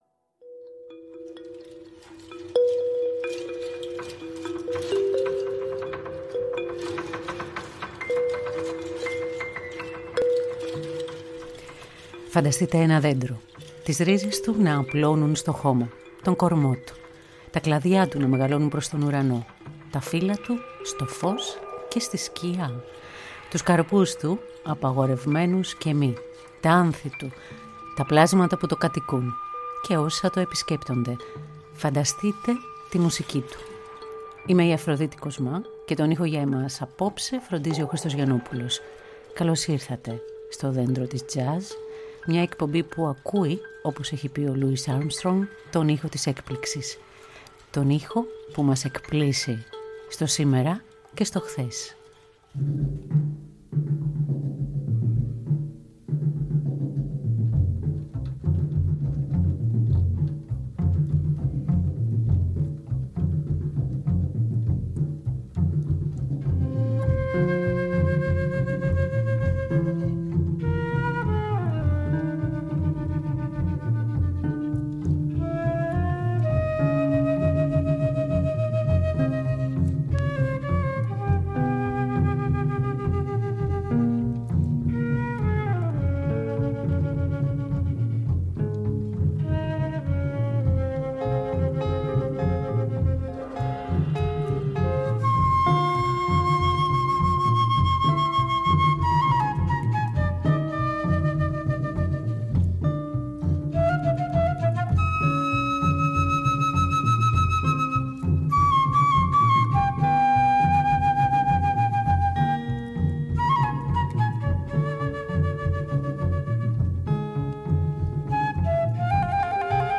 Μουσική Τζαζ